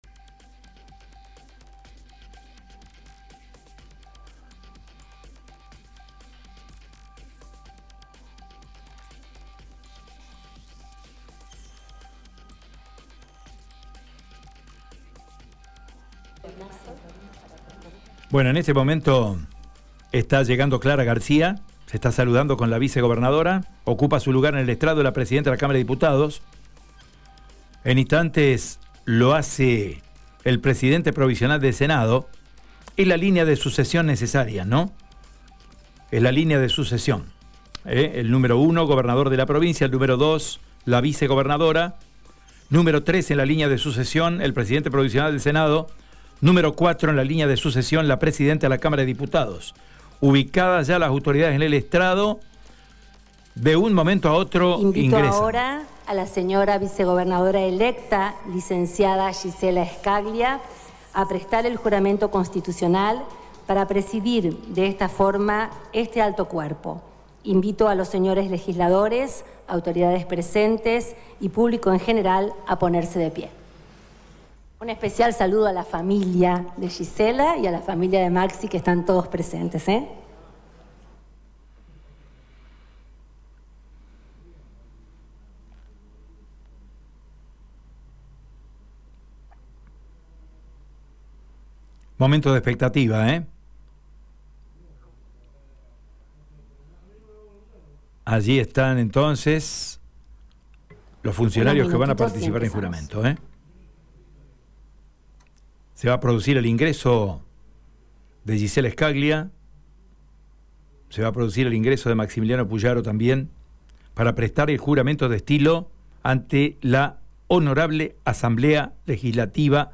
El oriundo de Huges juró y asumió como nuevo Gobernador de la provincia de Santa Fe.
Escucha la palabra de Maximiliano Pullaro en Radio EME:
DISCURSO-PULLARO.mp3